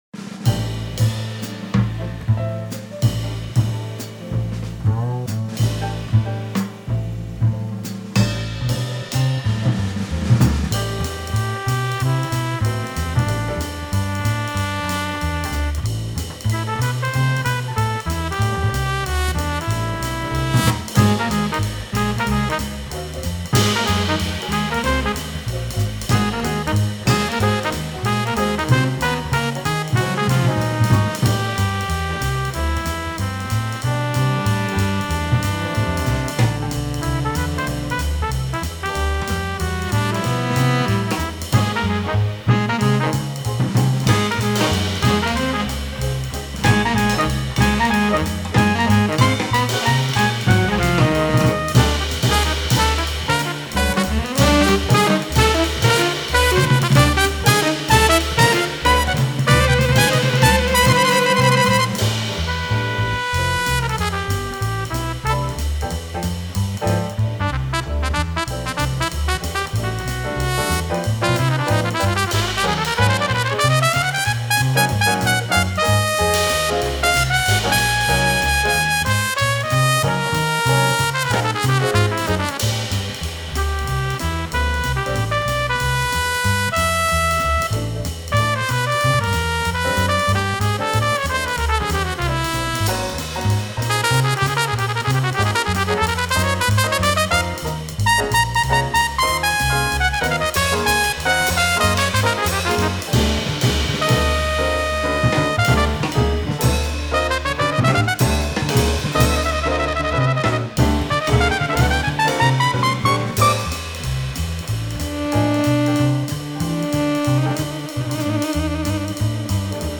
Stepping Over (straight ahead jazz quintet)